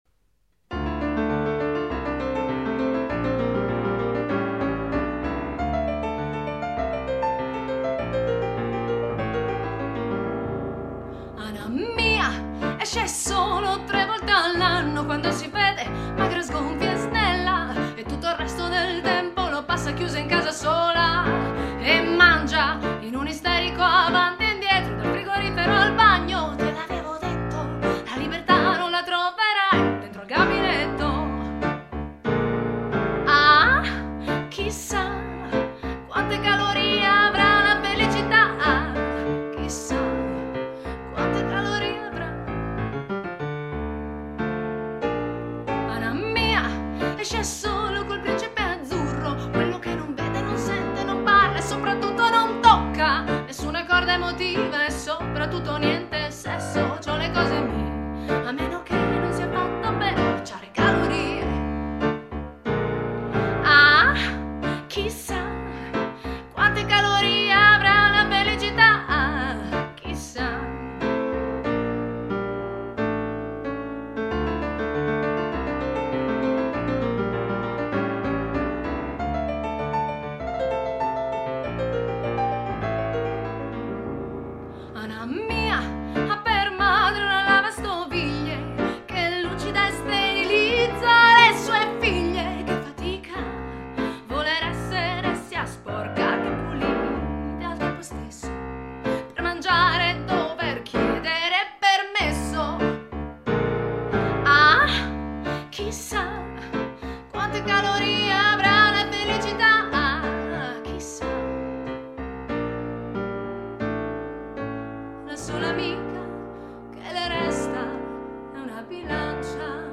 Voce
Chitarra acustica